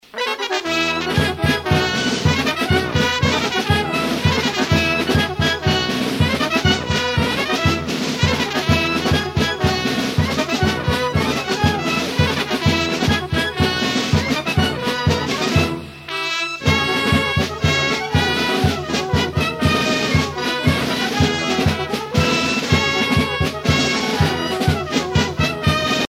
circonstance : carnaval, mardi-gras
Pièce musicale éditée